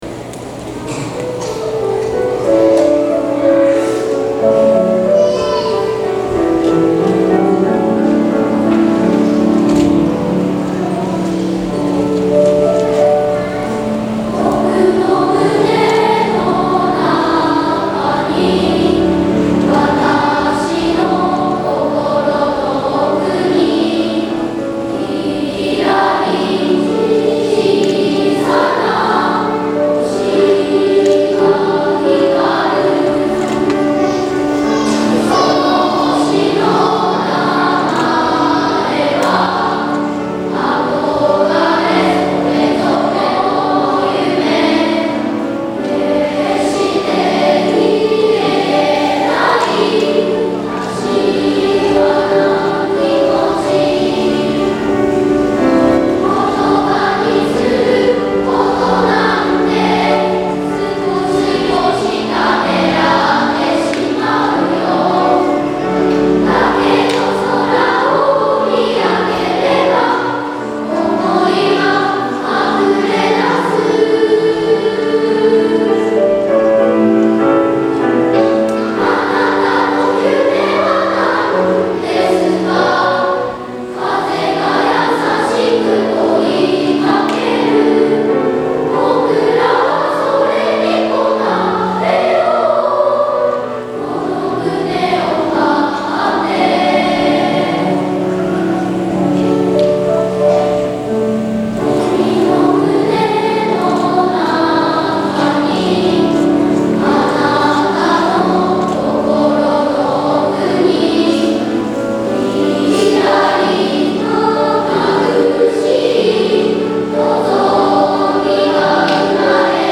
大空創立記念コンサート
アルトとソプラノの美しいハーモニーは、高学年として１〜４年生を包み込むような優しい歌声でした。